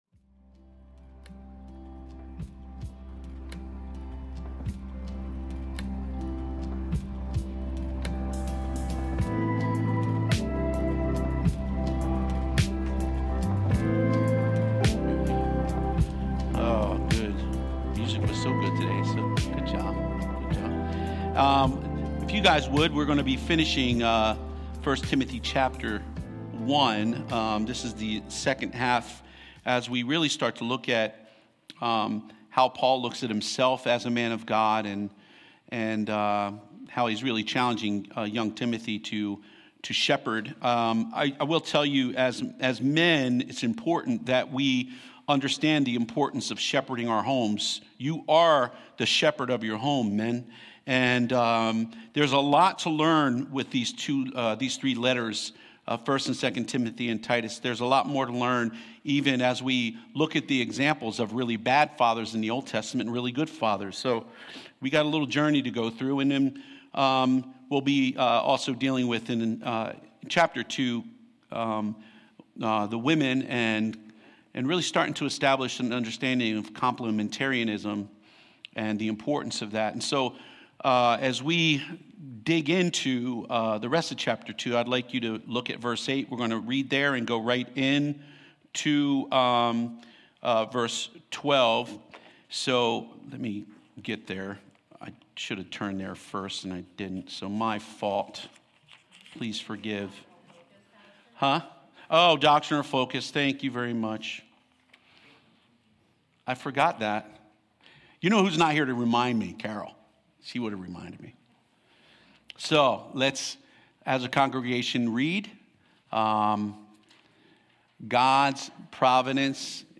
Sermons | Mount Eaton Church